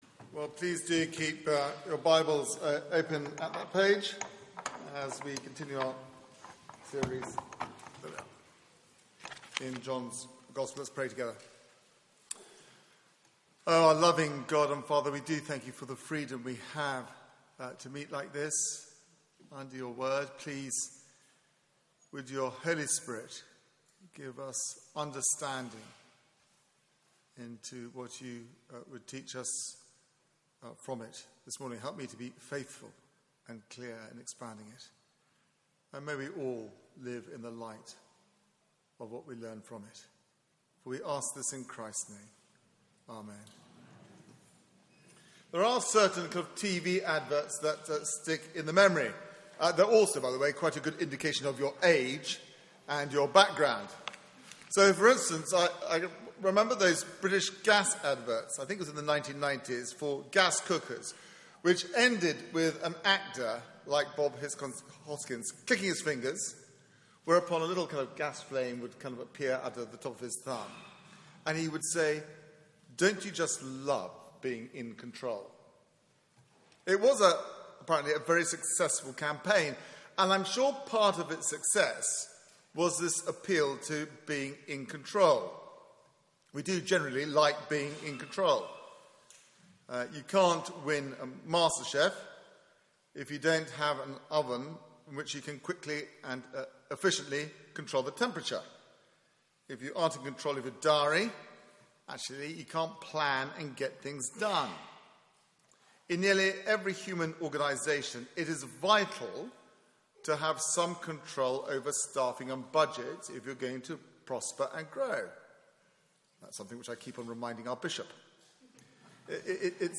Media for 9:15am Service on Sun 22nd May 2016
Series: The gathering storm Theme: The betrayal Sermon